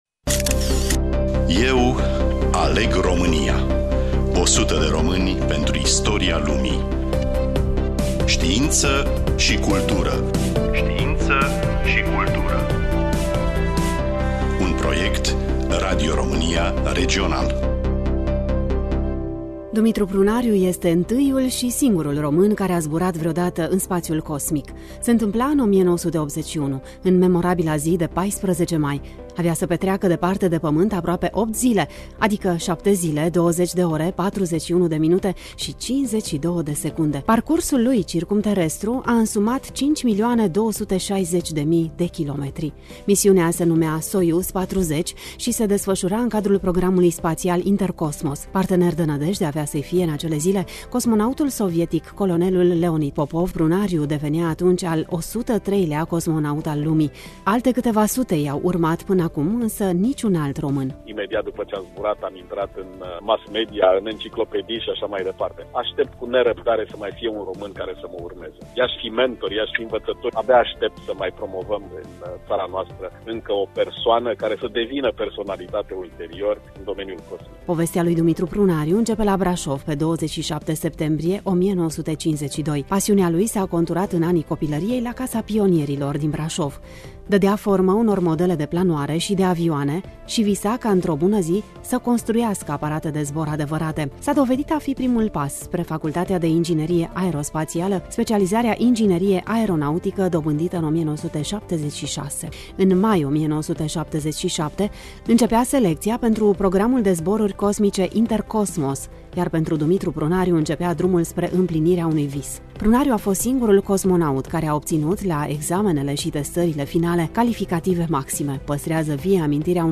Studioul: Radio România Tg. Mureş